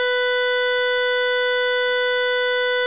Le fonctionnement est bizarre, car les sons qui sortent sont différents après chaque utilisation - mais pas tout le temps.
Vous aussi vous voulez utiliser le Synthétiseur Aléatoire du Microcosme ?